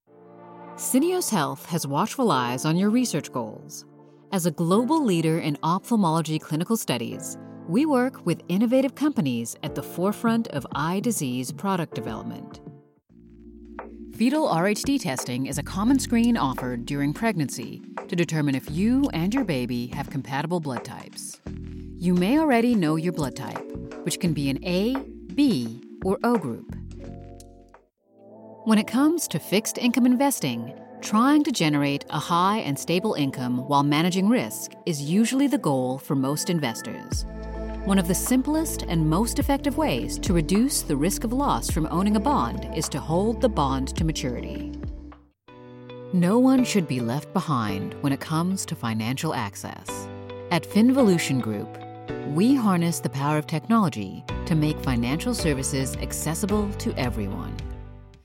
Corporate Narration
Middle Aged
My warm and confident delivery ensures your message resonates with your audience.